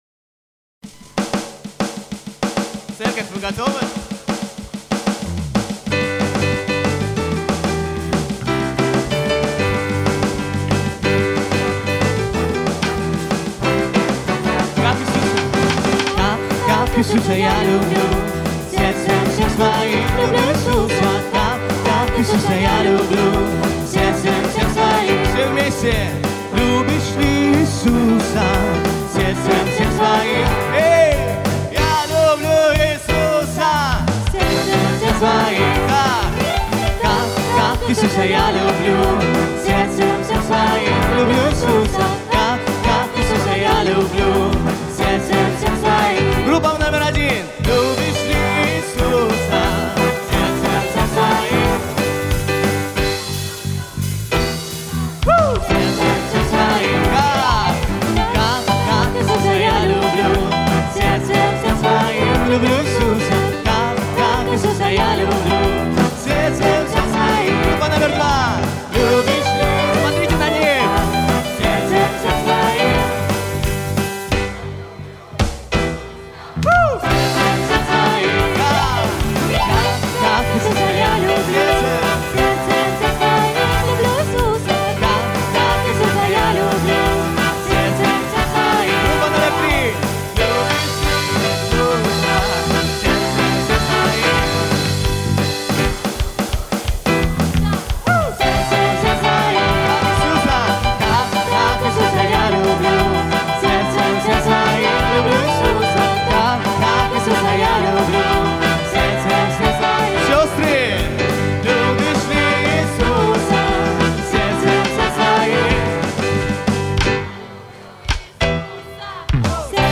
148 просмотров 185 прослушиваний 10 скачиваний BPM: 176